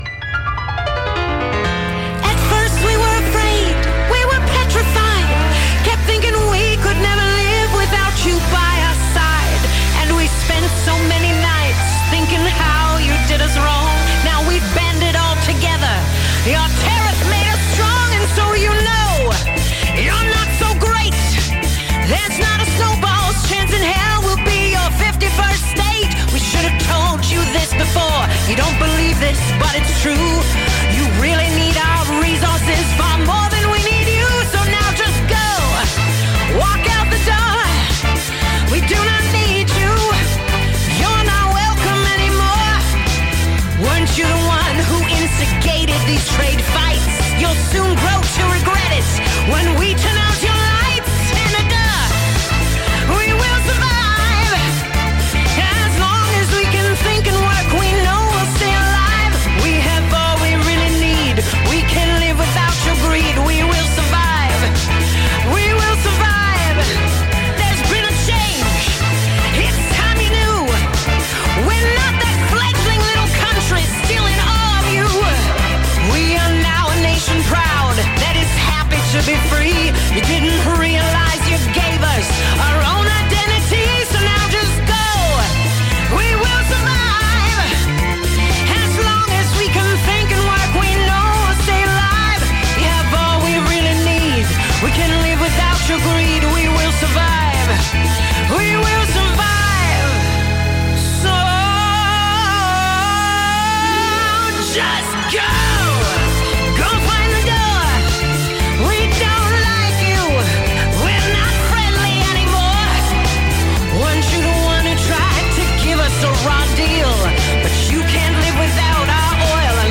parody